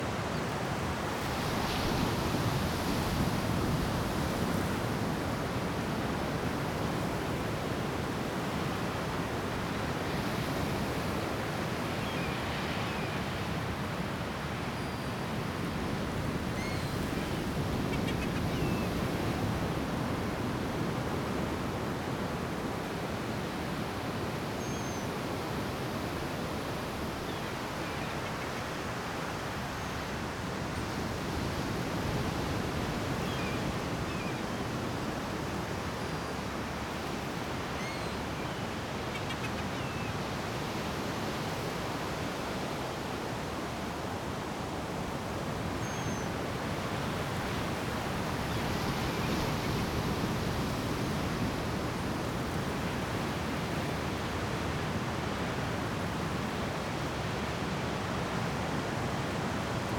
BGS Loops
Beach.ogg